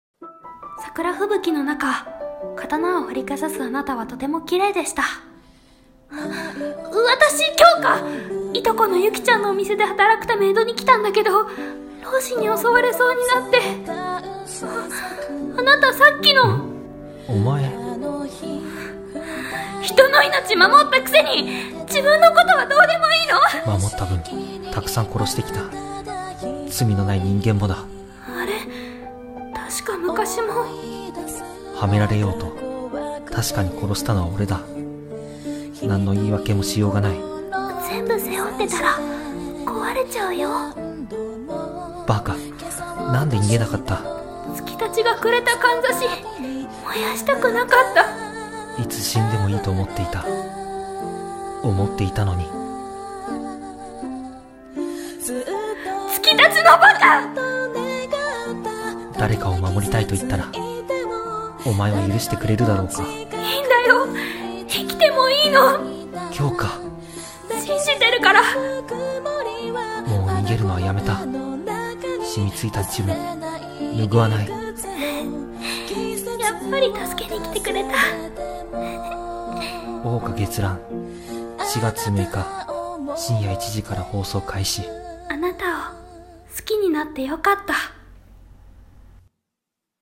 【アニメ告知CM風声劇台本】桜花月乱【２人声劇】